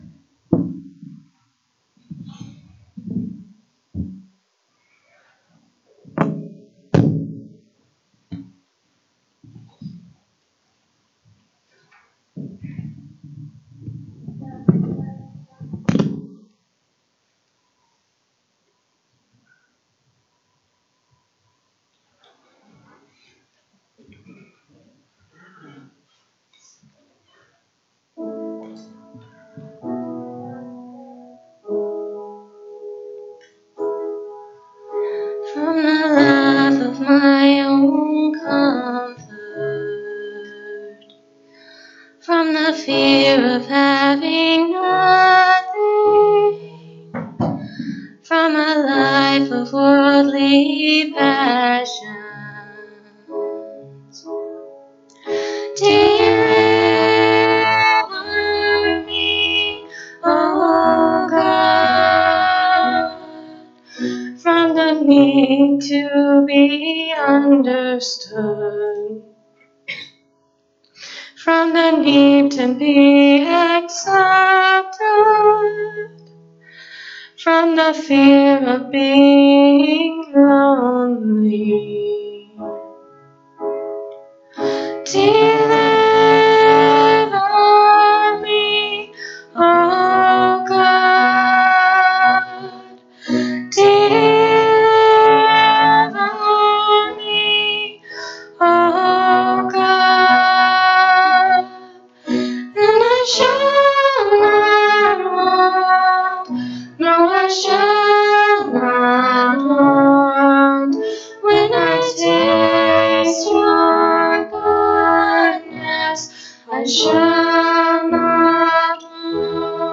Lexington Restoration Branch - March 30, 2025 Service - Playeur